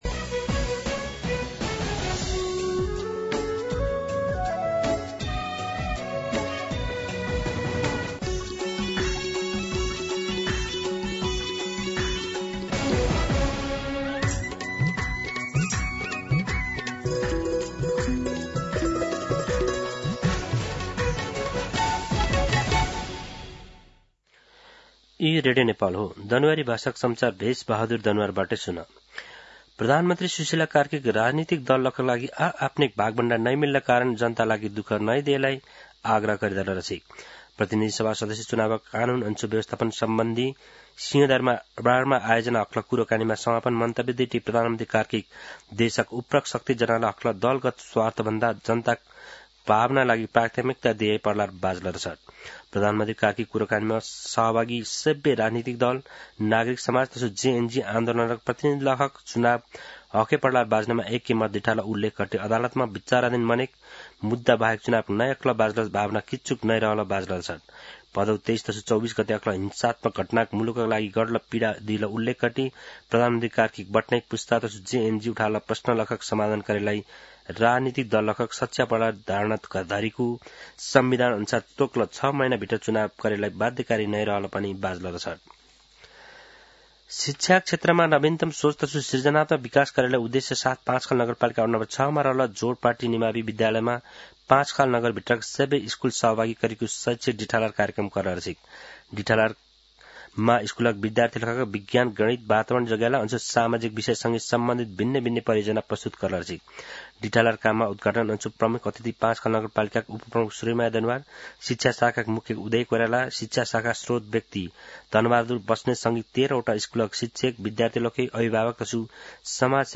An online outlet of Nepal's national radio broadcaster
दनुवार भाषामा समाचार : ८ पुष , २०८२
Danuwar-News-09-8.mp3